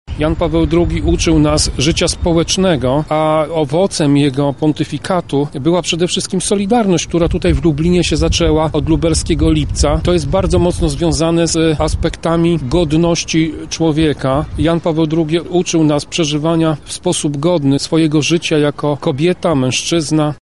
• mówi Tomasz Pitucha, radny PiS z Lublina.